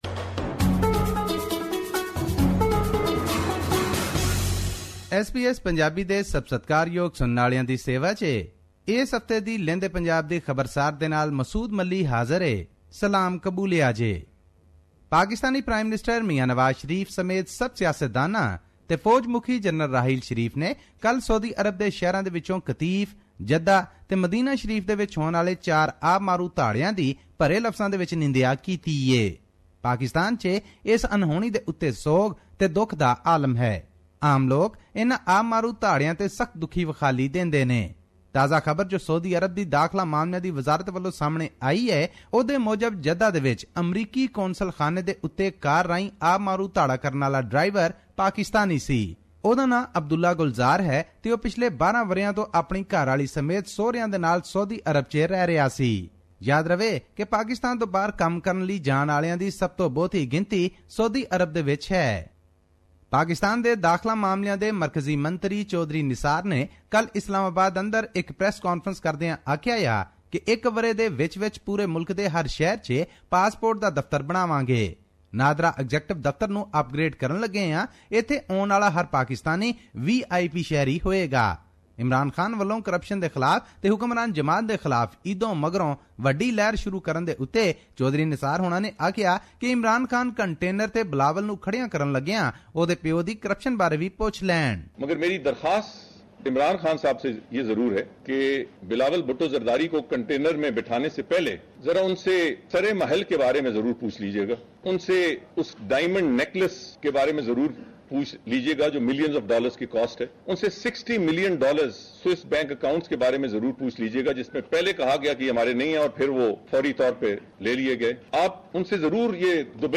His report was presented on SBS Punjabi program on Tuesday, July 6, 2016, which touched upon issues of Punjab and national significance in Pakistan.